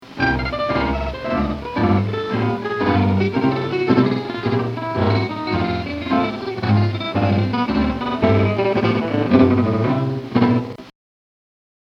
Below are two slowed down versions of the lick in case anybody else is interested in working on this.
The way I make it out, it is a DDU pattern on the two top strings, with a new note on each U stroke...
%22I Saw Stars%22 triplets half speed.mp3